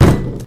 combobreak.wav